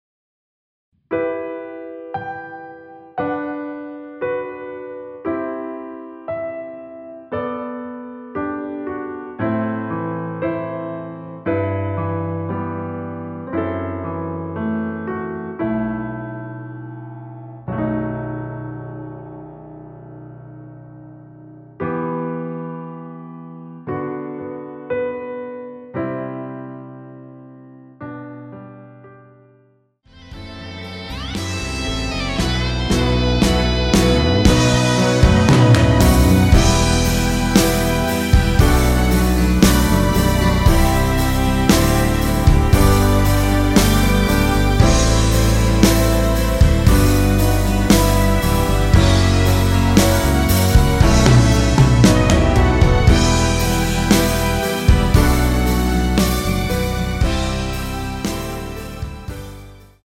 원키에서(-3)내린 MR입니다.
Em
앞부분30초, 뒷부분30초씩 편집해서 올려 드리고 있습니다.
중간에 음이 끈어지고 다시 나오는 이유는